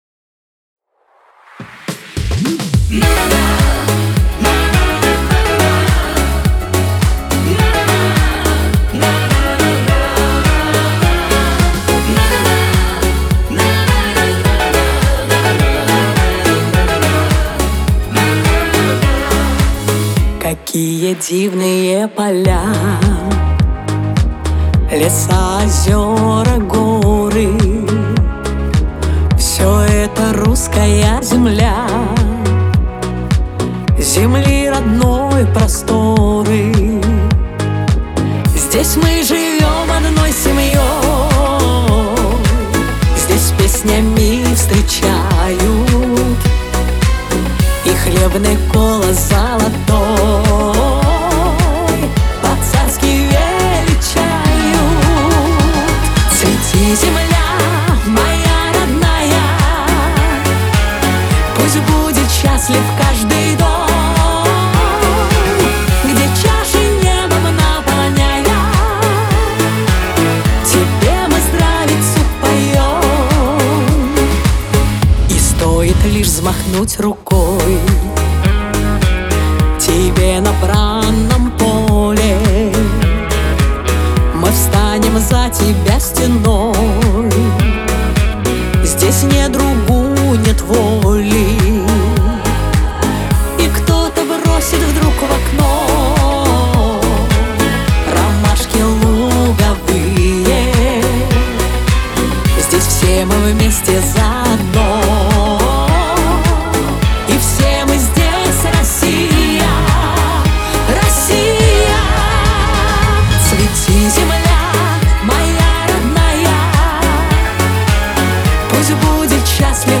диско , pop , Лирика , эстрада